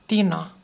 Ääntäminen
Synonyymit astiatina Ääntäminen Tuntematon aksentti: IPA: [ˈt̪inɑ] Haettu sana löytyi näillä lähdekielillä: suomi Käännös Substantiivit 1. калай {m} (kaláj) Määritelmät Substantiivit metallinen alkuaine , jonka kemiallinen merkki on Sn .